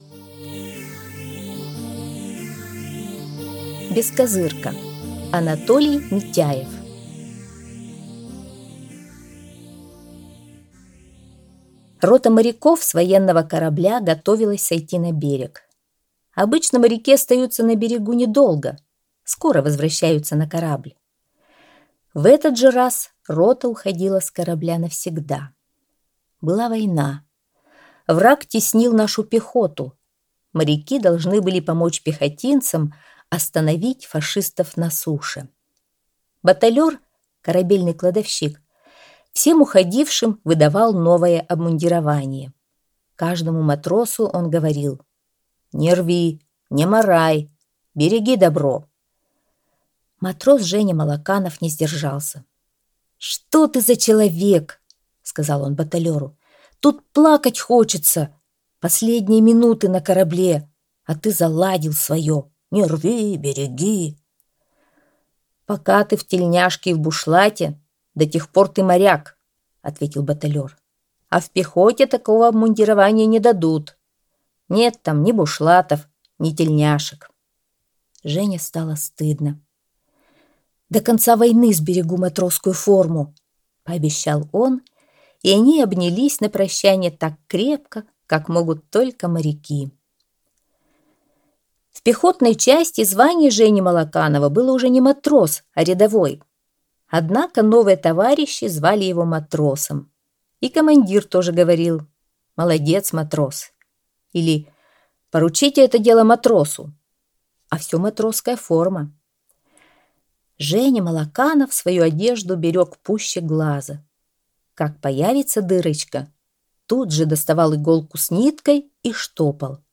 Аудиорассказ «Бескозырка»